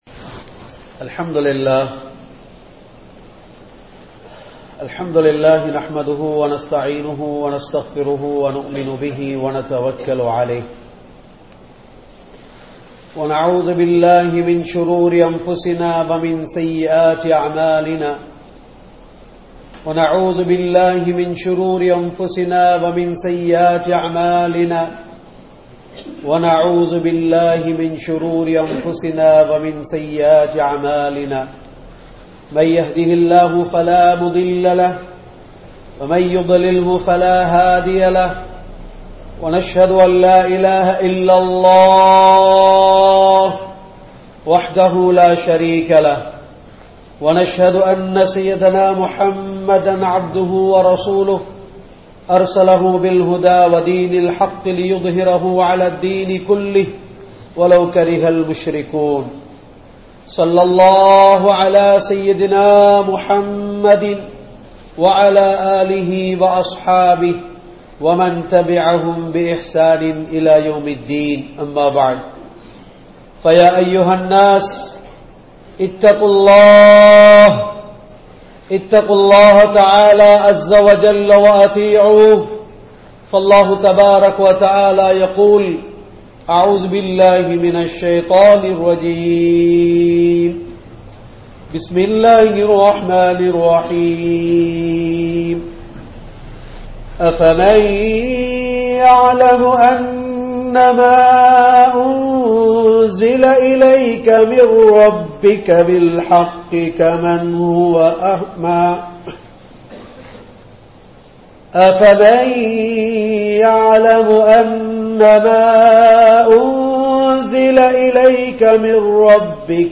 Vaalkaiyai Mannaakkiyavarhal (வாழ்க்கையை மண்ணாக்கியவர்கள்) | Audio Bayans | All Ceylon Muslim Youth Community | Addalaichenai